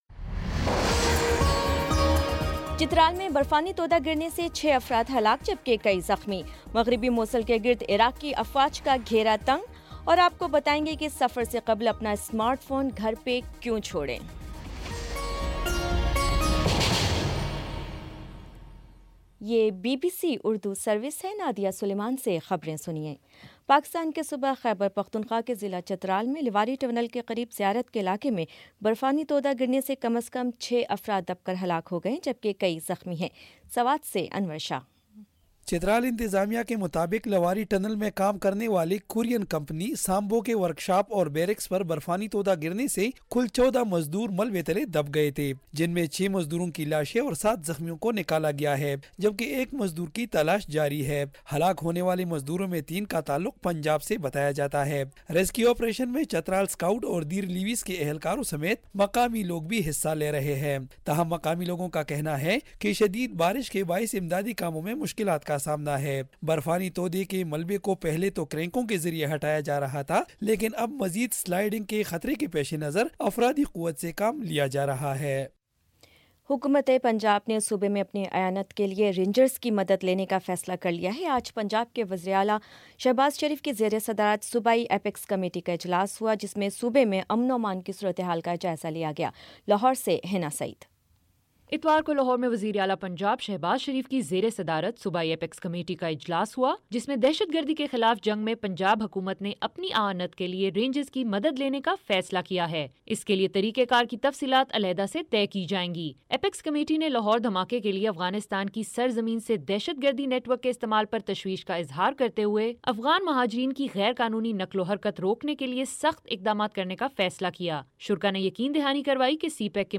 فروری 19 : شام پانچ بجے کا نیوز بُلیٹن